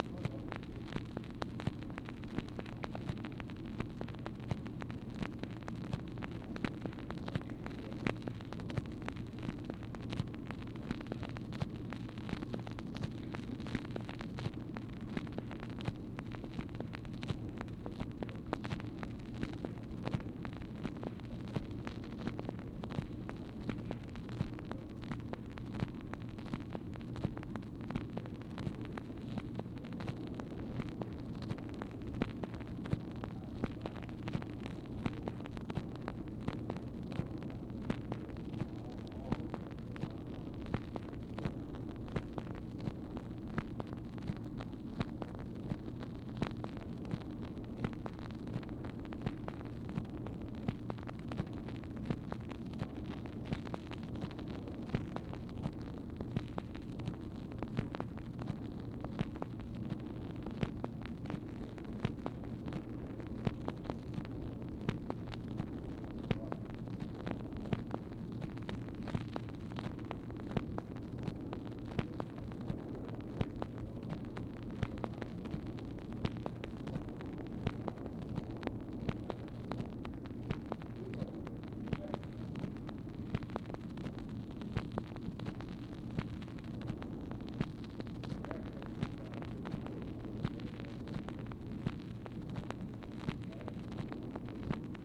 OFFICE CONVERSATION, March 21, 1964
Secret White House Tapes | Lyndon B. Johnson Presidency